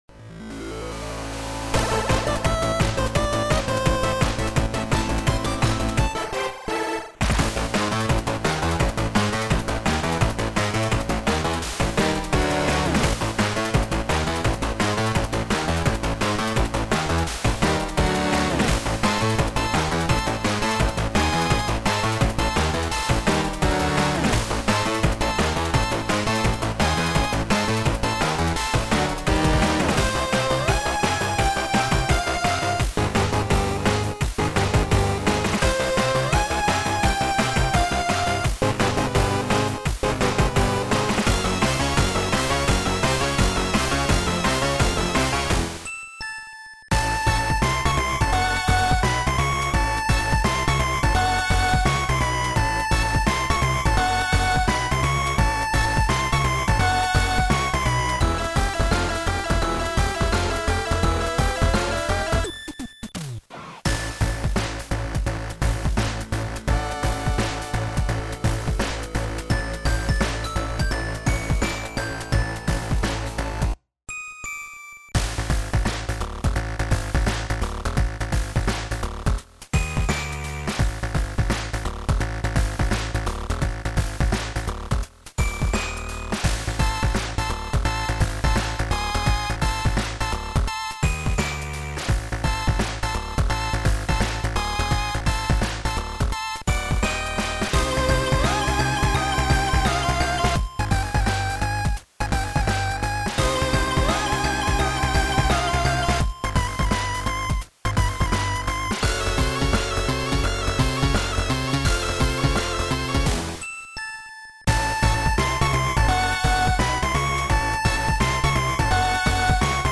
How did you do the bass, is that a filtered FDS waveform?